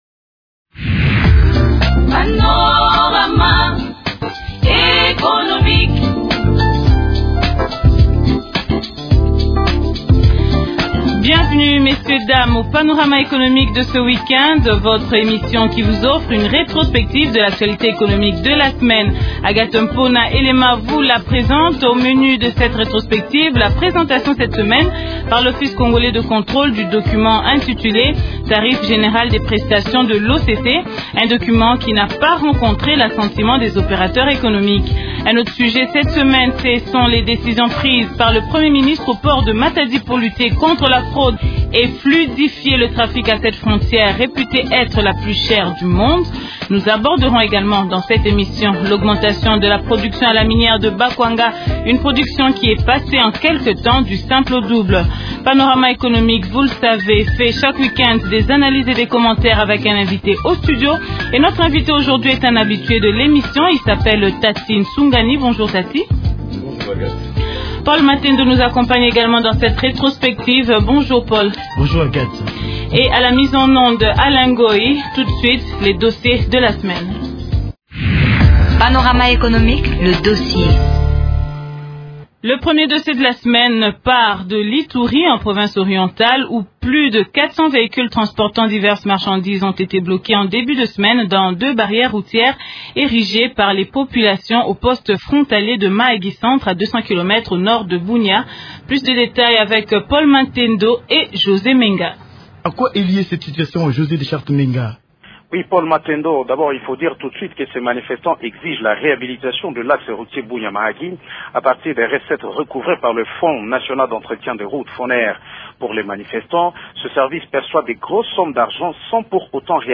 un analyste économiste.